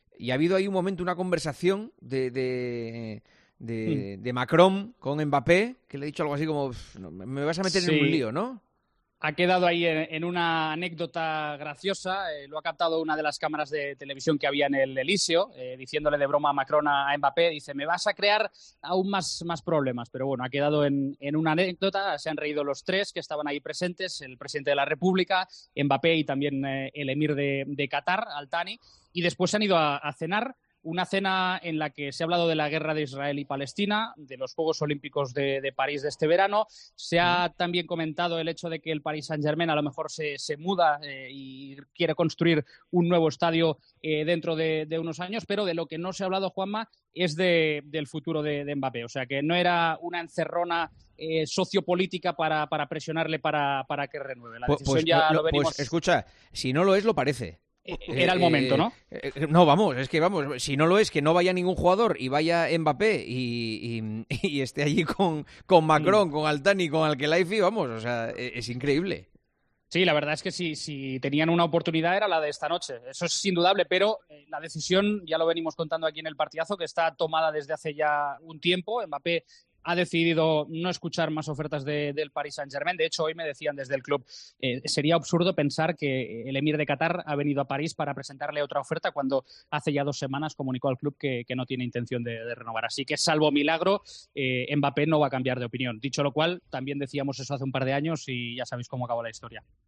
El encuentro de Estado en el que participó el todavía jugador del PSG se abordó en El Partidazo de COPE, donde el presentador y director dio su opinión